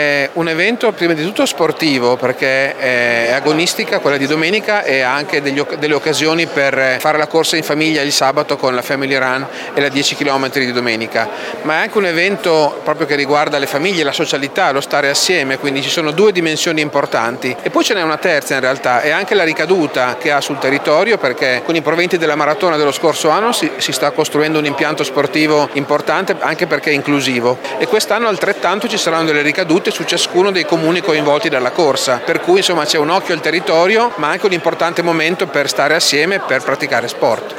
Il sindaco di Maranello Luigi Zironi: